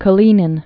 (kə-lēnĭn, kəl-yē-)